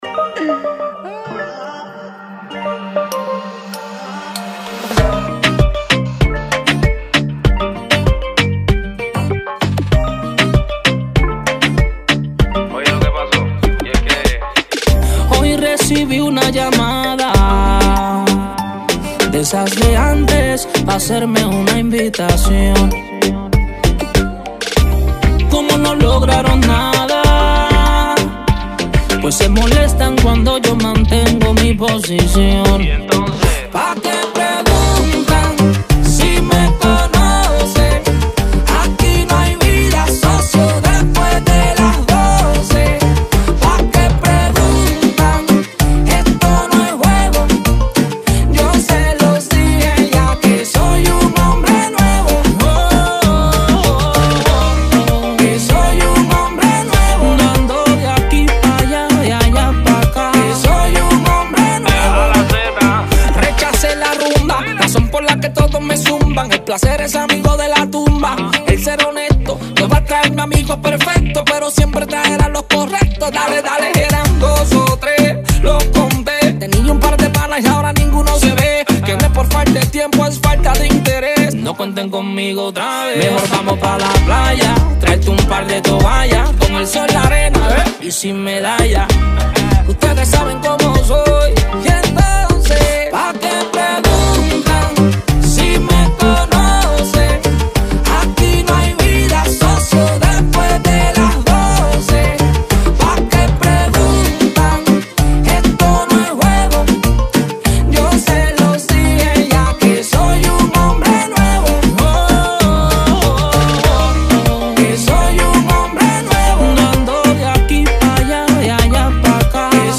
Videos Cristianos